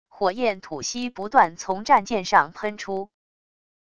火焰吐息不断从战舰上喷出wav音频